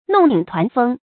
弄影團風 注音： ㄋㄨㄙˋ ㄧㄥˇ ㄊㄨㄢˊ ㄈㄥ 讀音讀法： 意思解釋： 形容心魂不定。